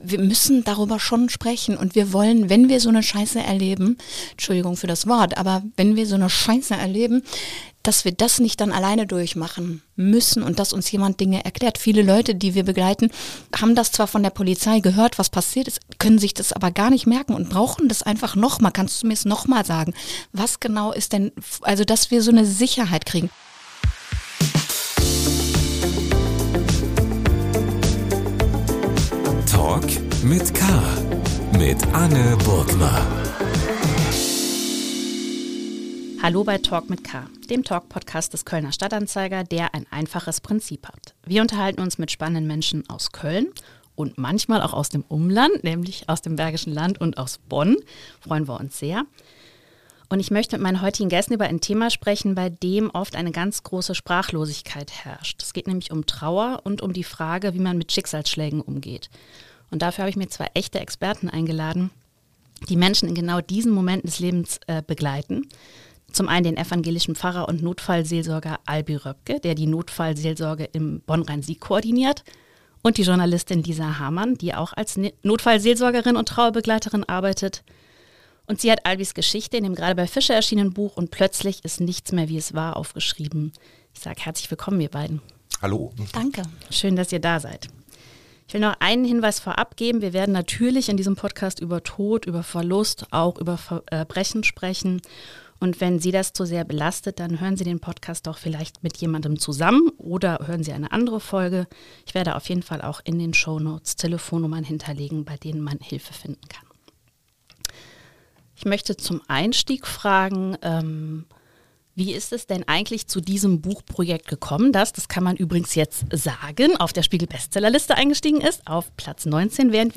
der Talk-Podcast des Kölner Stadt-Anzeiger Podcast